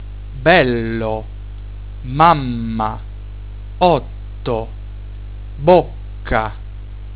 Geminaten/Doppelkonsonanten:   Doppelkonsonanten wie "-mm-", "-nn-", "-ll-", "-tt-" etc. werden im Italienischen gelängt ausgesprochen.
Ton    bello, mamma, otto, bocca...